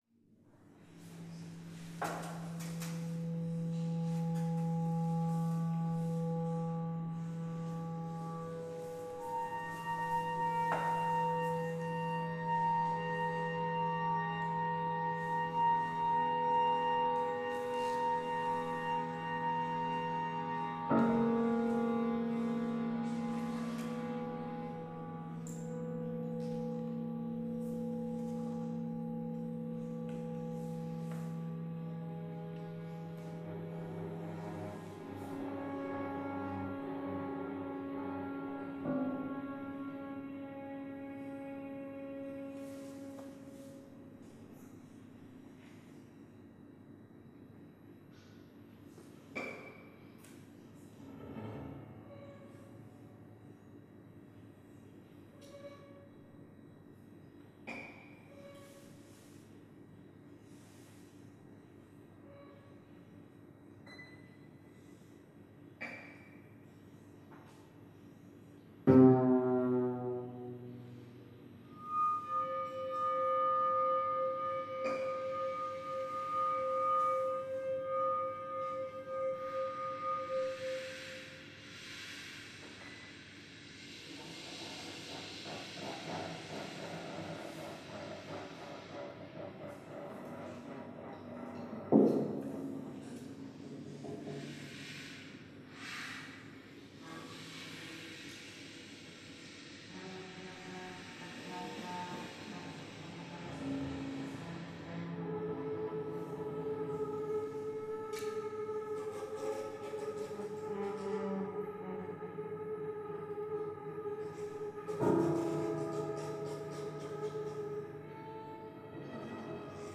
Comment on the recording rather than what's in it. Performance at The Shoe Factory, Nicosia